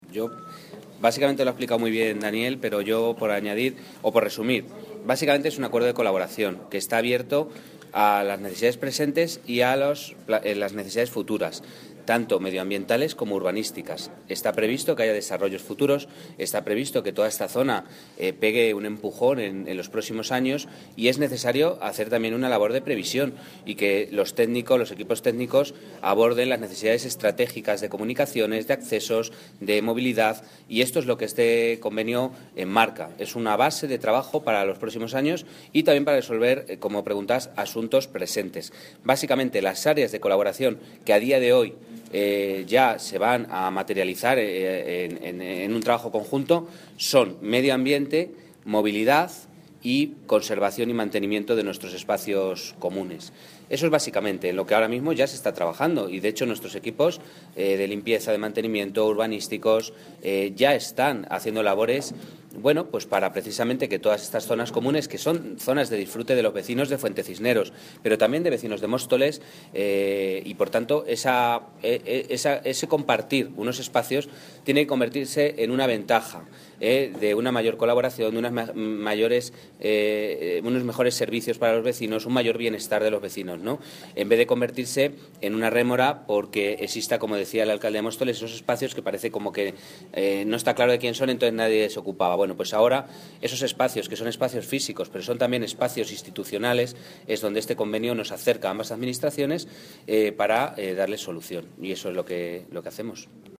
Audio - David Pérez (Alcalde de Alcorcón) Sobre Firma Acuerdo